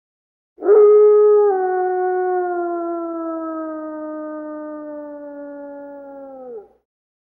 Звуки волка
Вой одинокого